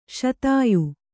♪ śatāyu